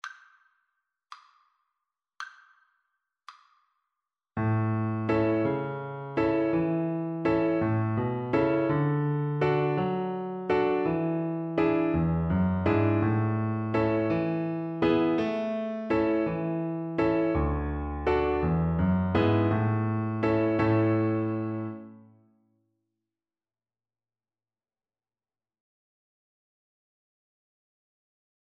A major (Sounding Pitch) (View more A major Music for Violin )
6/8 (View more 6/8 Music)
Joyfully .=c.76